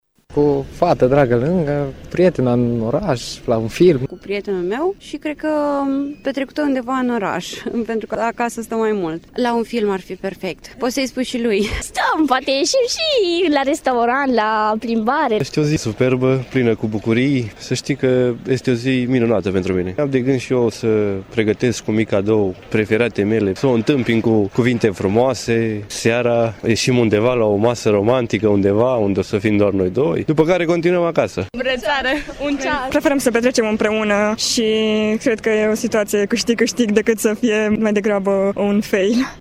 Tinerii sibieni, care agrează această sărbătoare și-au făcut deja planuri cum vor petrece:
stiri-5-febr-valentines-vox.mp3